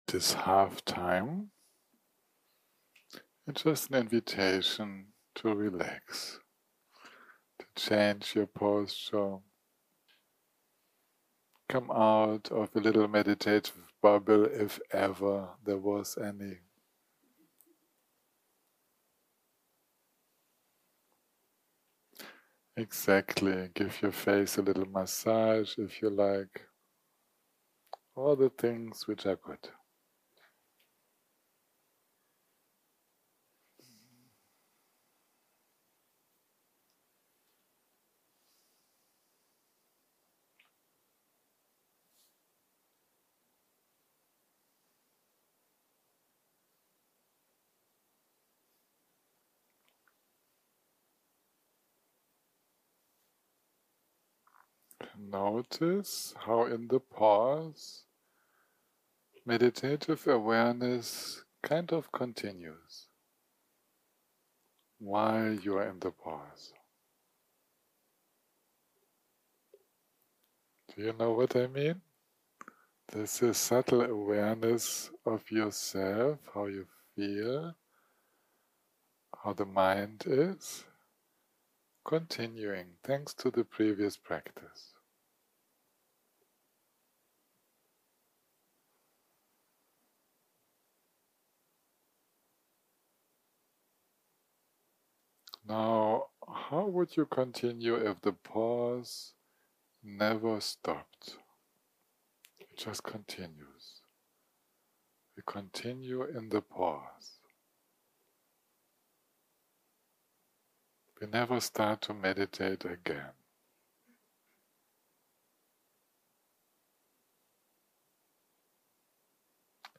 יום 5 - הקלטה 21 - בוהריים - מדיטציה מונחית - Six Dhammas of Tilopa - part 1 0:00 0:00 סוג ההקלטה: סוג ההקלטה: מדיטציה מונחית שפת ההקלטה: שפת ההקלטה: אנגלית